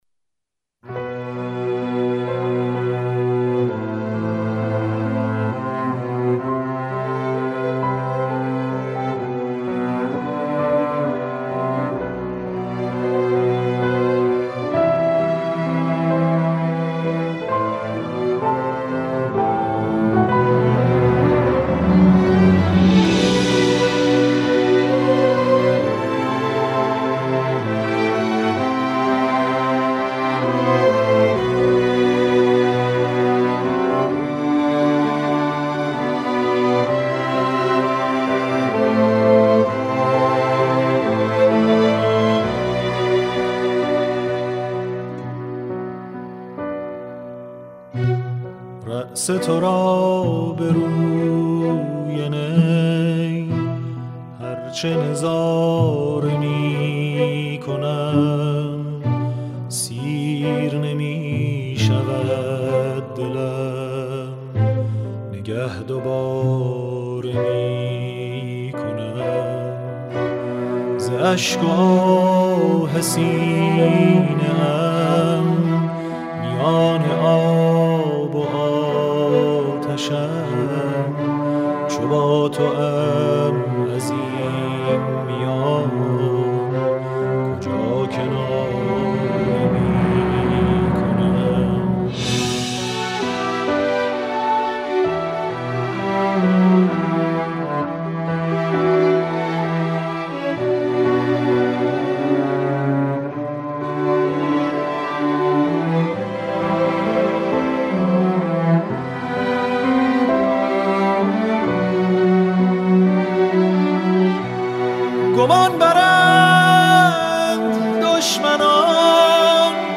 معرفی نمونه‌های موفق آثار موسیقی عاشورایی/1